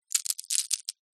Здесь собраны уникальные записи: от мягкого шелеста скорлупы до насыщенного хруста при разламывании.
Звуки фисташек: трясем несколько фисташек в руке